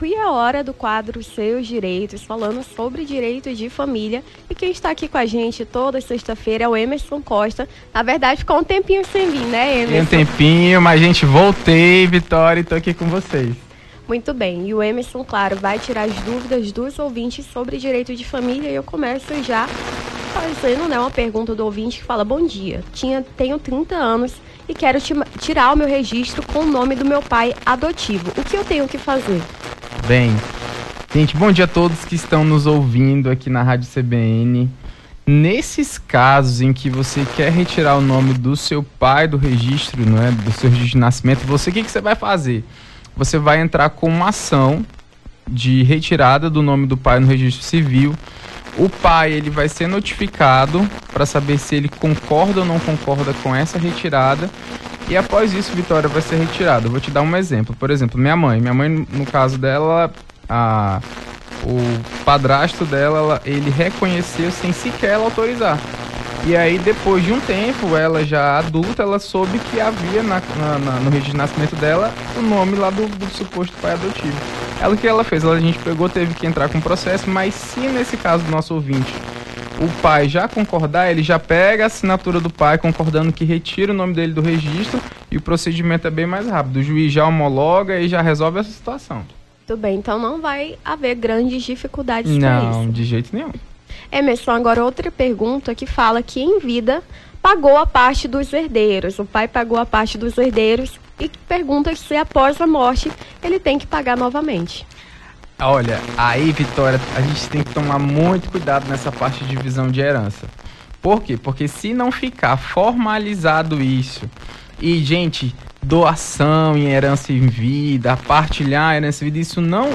as apresentadoras